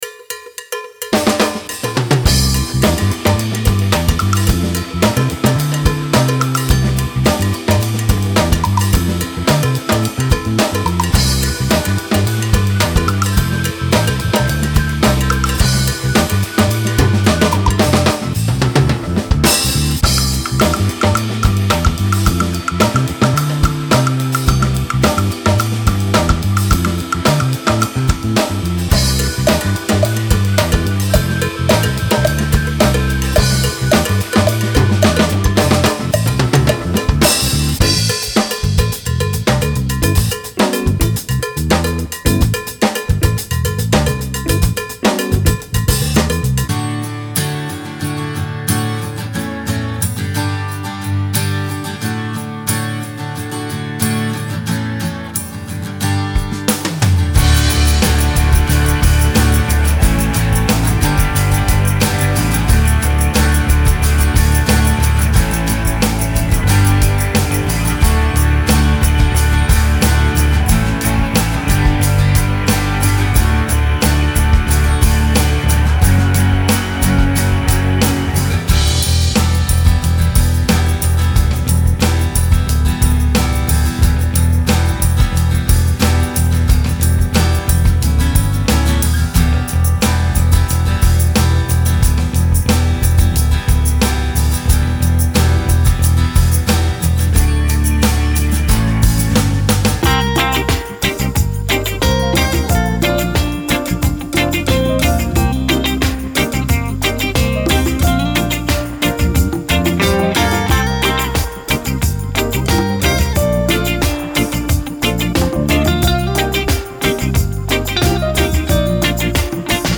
专为 Kontakt 8 打造、由技艺精湛的乐手录制的打击乐音色库
• 由专业乐手演奏并录制的Kontakt循环音轨
Bells_and_Jingles_Demo.mp3